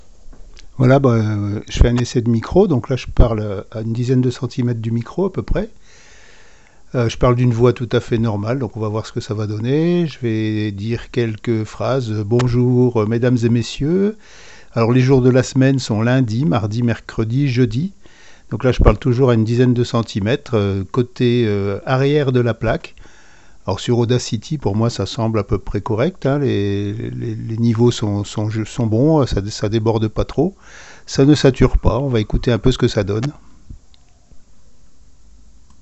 Exemple d’enregistrement avec Anavi Technology DEV MIC
Le niveau d’enregistrement est correct et le signal est bien symétrique.
La qualité est au rendez vous, comme sur un micro « classique » il faudrait un filtre anti-pop (vous savez cette espèce de chaussette qu’on place devant le micro) car on entend un peu les explosives (b, p…).